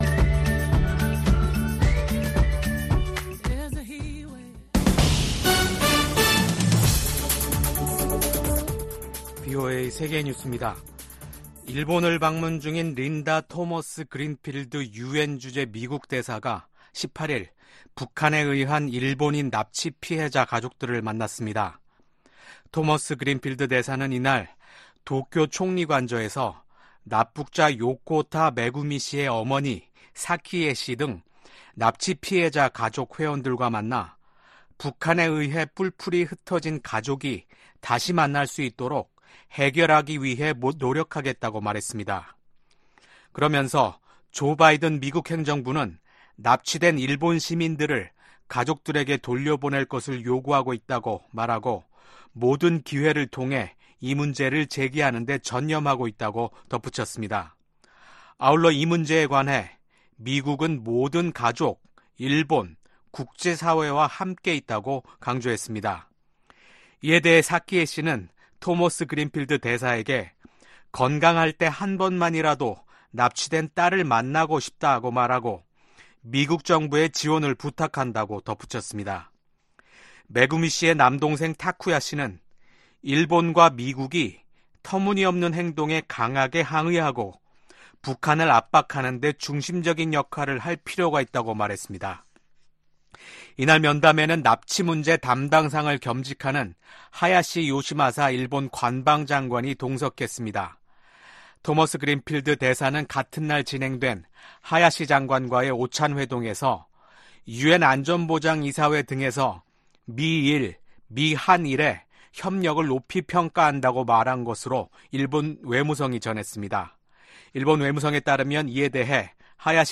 VOA 한국어 아침 뉴스 프로그램 '워싱턴 뉴스 광장' 2024년 4월 19일 방송입니다. 로이드 오스틴 미국 국방장관이 북한 김정은 정권의 계속된 도발에 대응해 주한미군의 준비태세를 강화하겠다는 의지를 밝혔습니다. 미국과 한국, 일본의 재무장관들이 북한 정권의 무기 프로그램에 대응하기 위한 제재 공조 방침을 재확인했습니다. 한일 두 나라 정상이 어제 전화통화를 갖고 한일, 미한일 공조를 계속 강화하기로 했습니다.